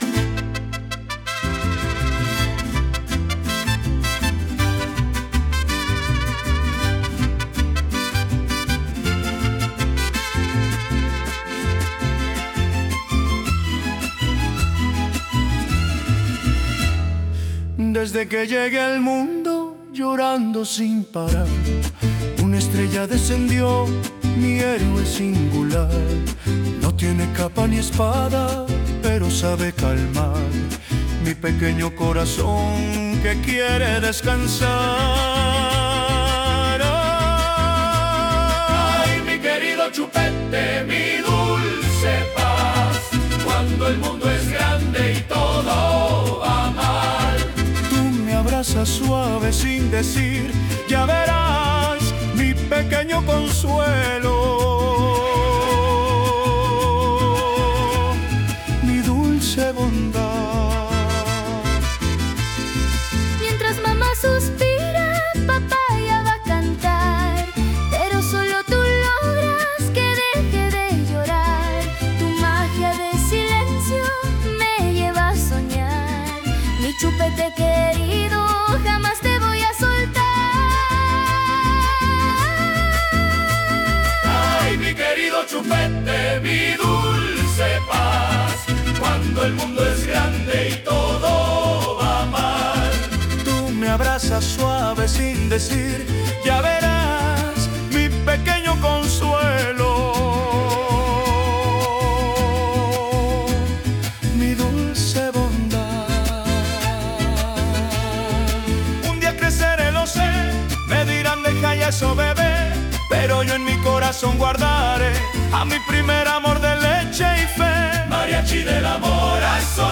Genre: Mariachi